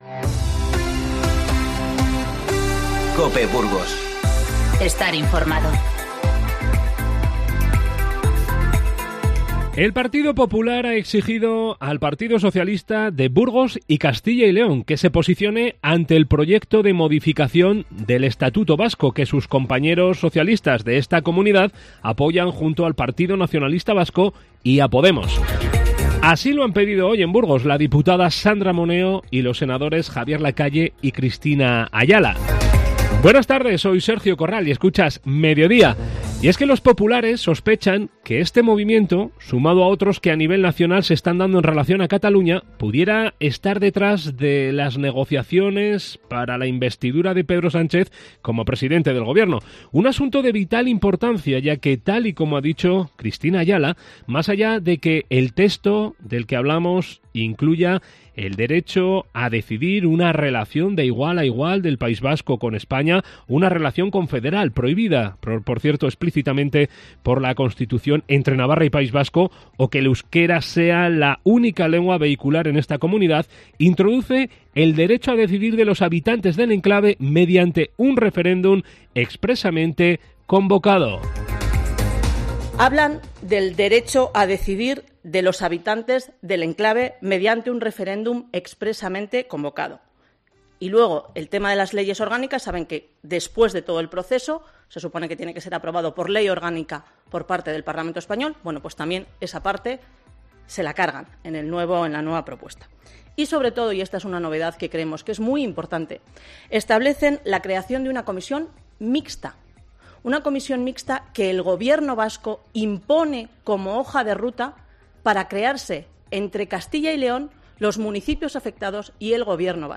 INFORMATIVO Mediodía 13-12-19